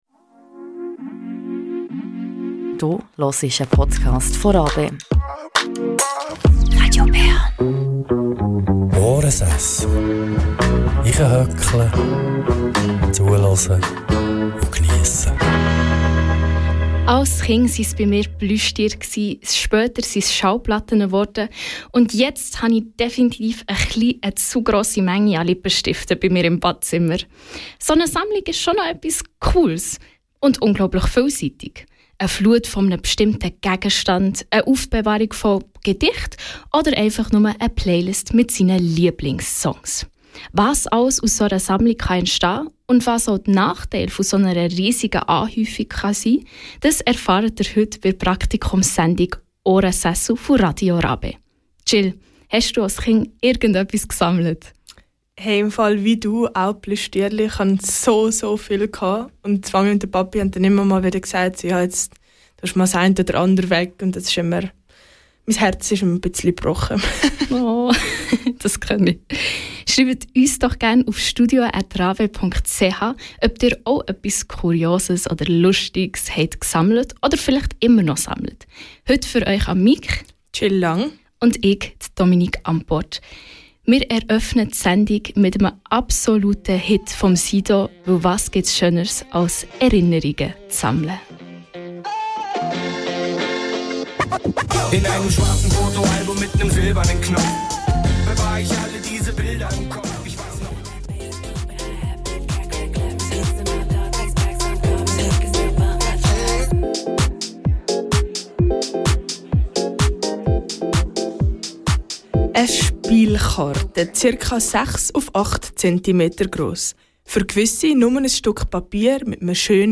Dieser Podcast ist ein Zusammenschnitt der live-Radio-Sendung Ohrensessel, produziert und präsentiert von den Praktikant:innen von Radio RaBe, Bern.